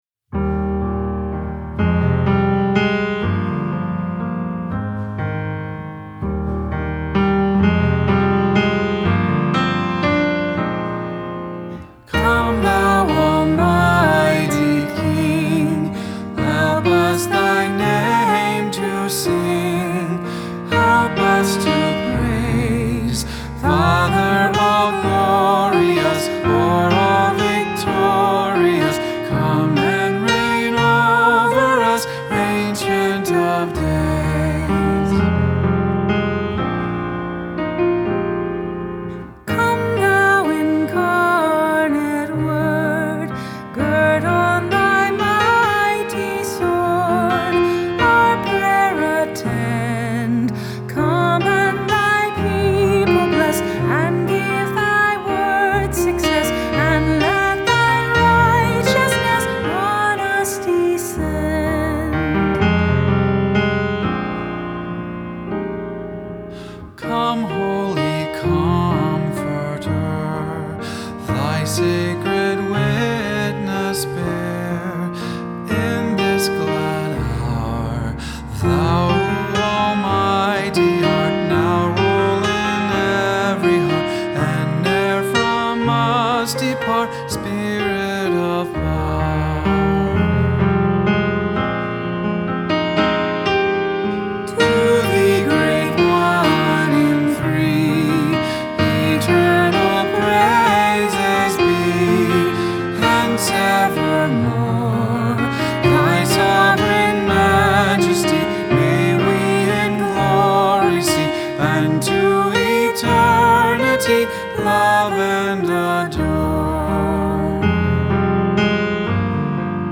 Hymn
The Hymnal Project